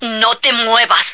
flak_m/sounds/female1/est/F1sitdown.ogg at d2951cfe0d58603f9d9882e37cb0743b81605df2
F1sitdown.ogg